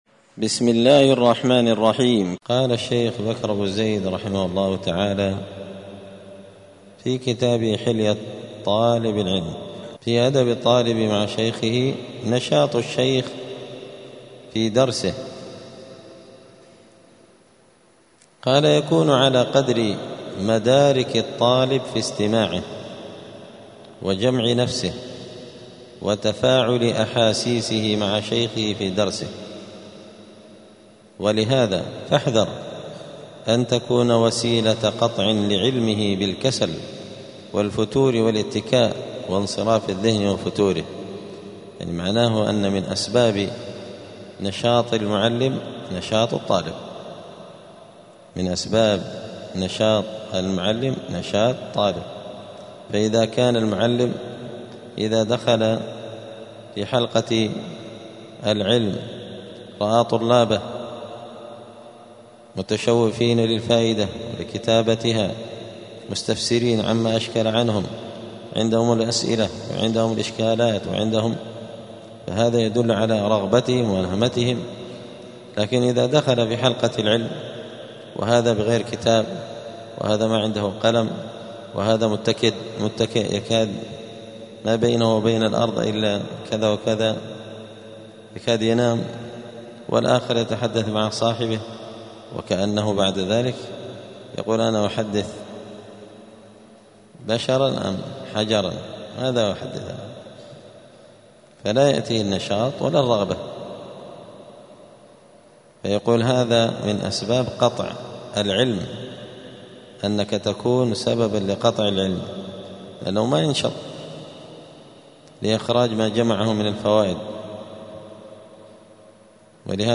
*الدرس الخامس والثلاثون (35) {فصل أدب الطالب مع شيخه نشاط الشيخ في درسه}*
35الدرس-الخامس-والثلاثون-من-كتاب-حلية-طالب-العلم.mp3